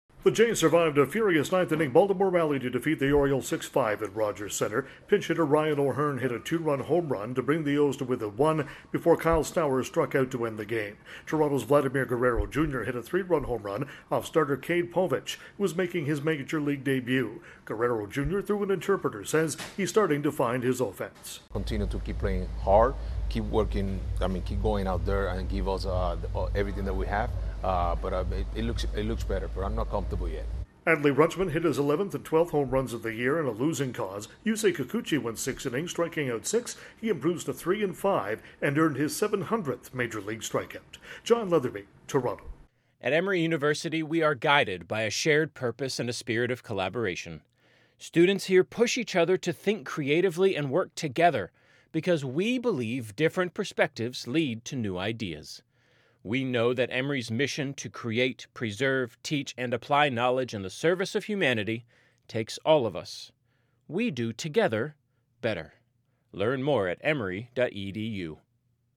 The Blue Jays earn a split of their four-game series. Correspondent
reports